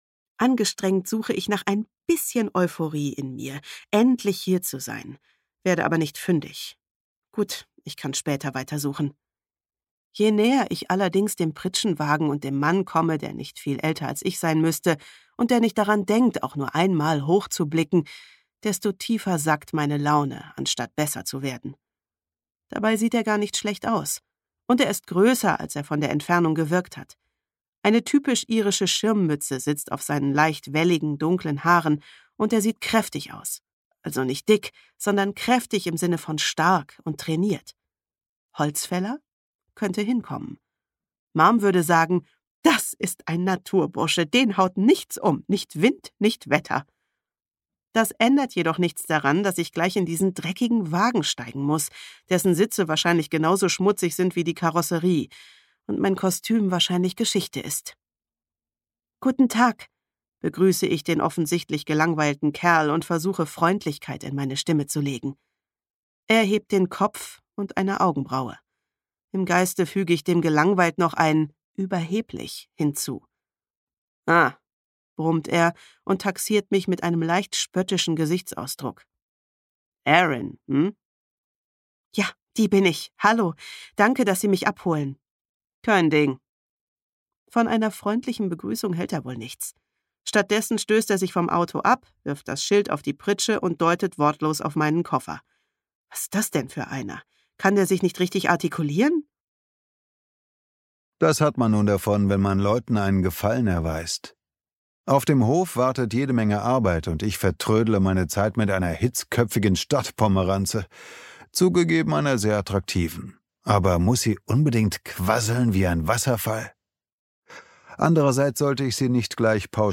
Irish Hope (DE) audiokniha
Ukázka z knihy